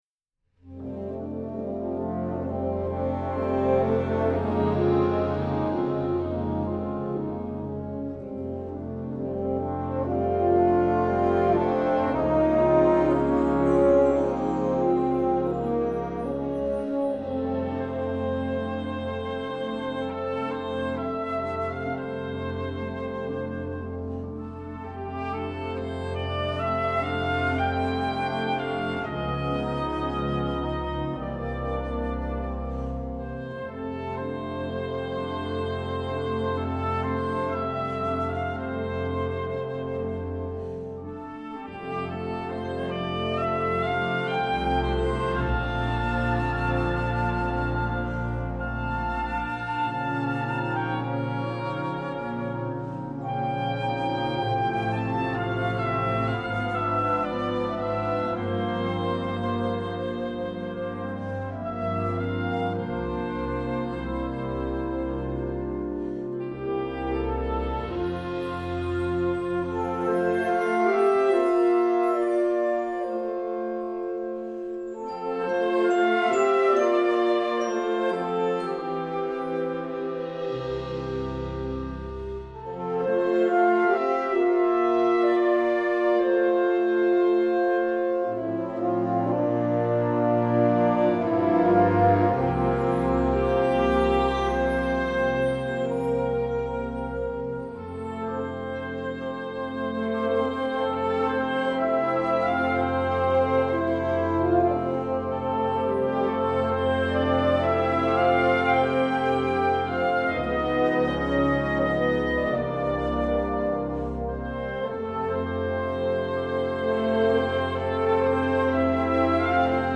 classical, pop, children, instructional, contemporary